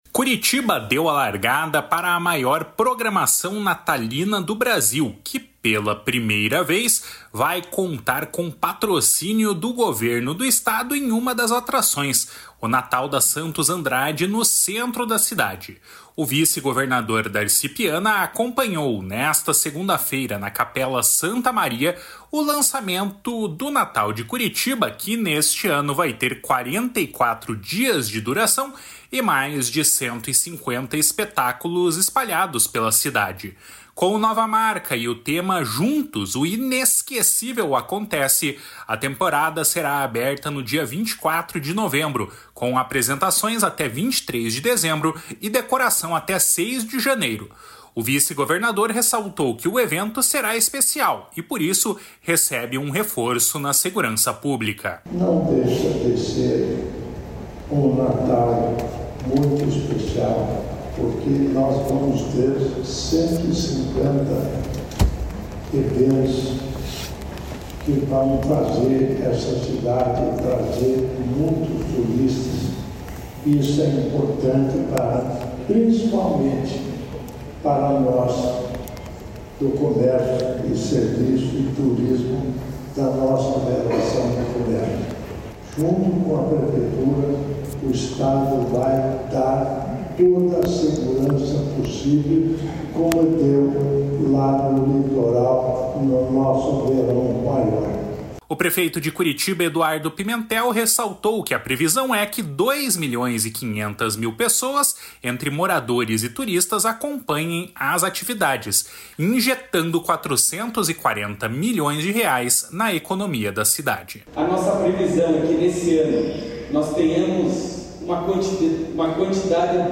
// SONORA DARCI PIANA //
O prefeito de Curitiba, Eduardo Pimentel, ressaltou que a previsão é que dois milhões e 500 mil pessoas, entre moradores e turistas, acompanhem as atividades, injetando 440 milhões de reais na economia da cidade. // SONORA EDUARDO PIMENTEL //